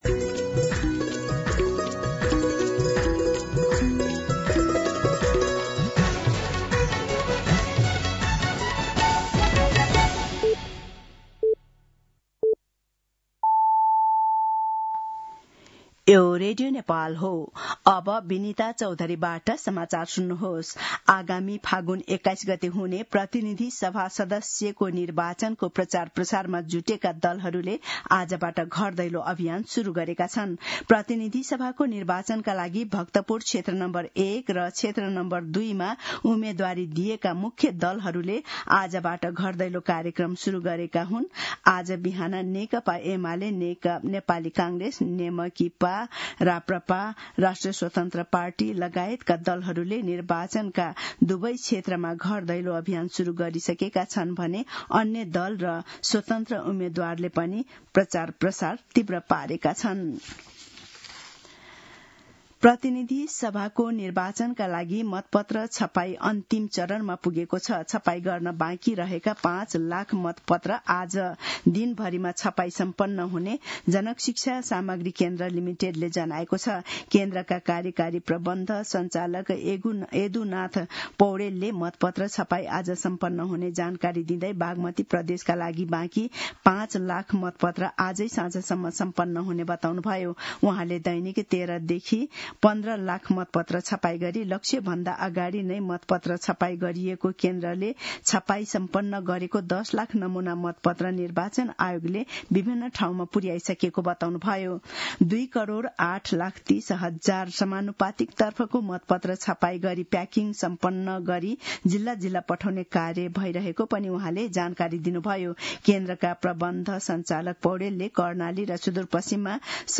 दिउँसो १ बजेको नेपाली समाचार : ४ फागुन , २०८२